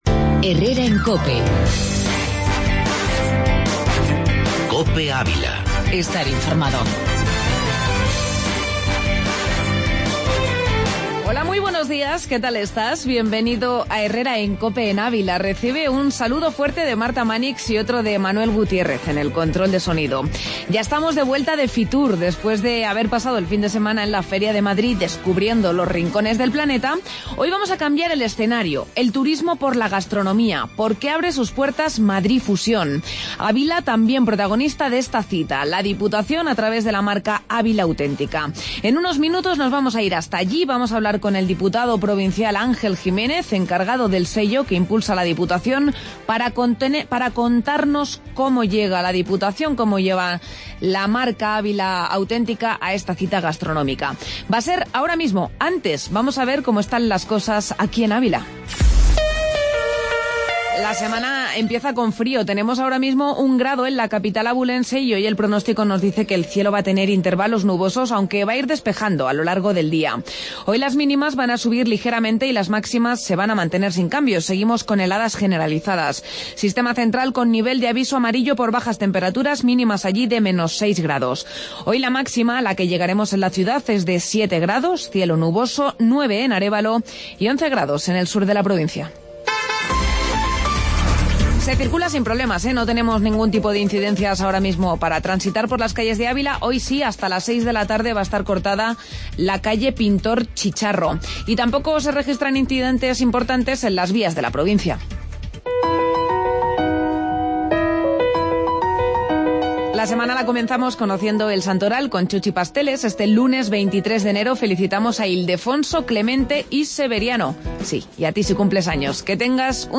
AUDIO: Entrevista Avila Autentica